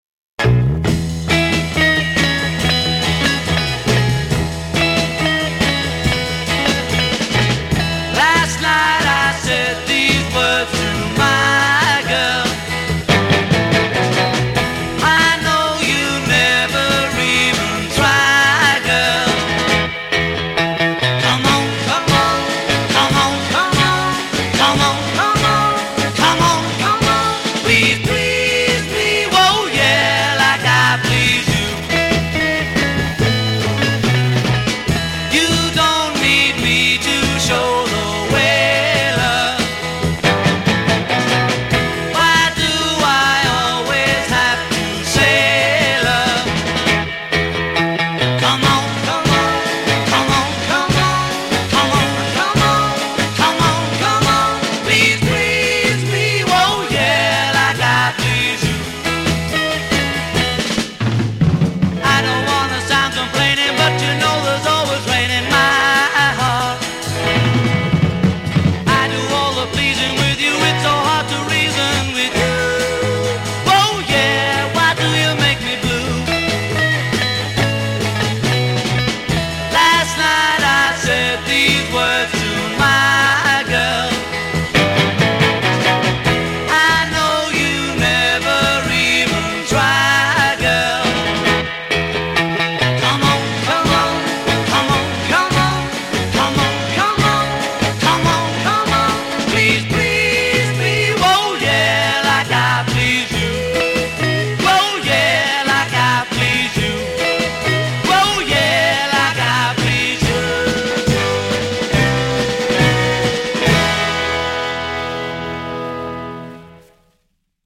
Рок музыка